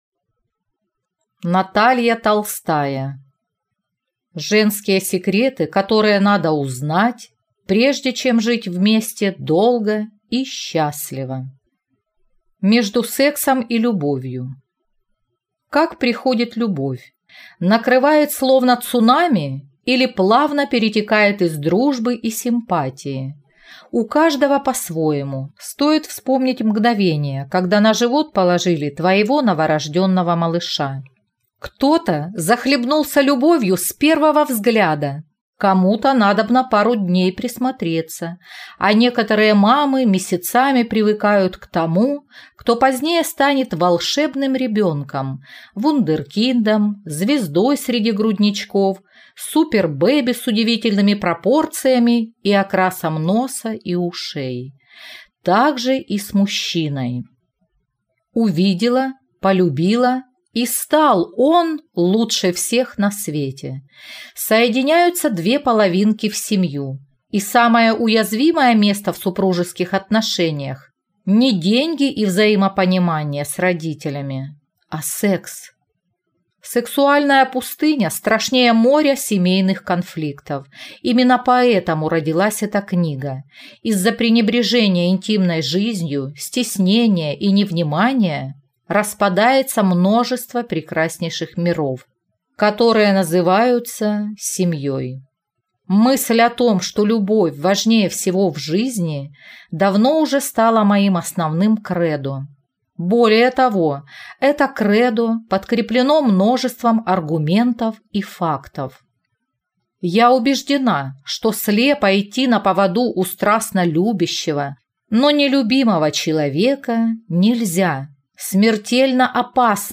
Аудиокнига Женские секреты, которые надо узнать, прежде чем жить вместе долго и счастливо | Библиотека аудиокниг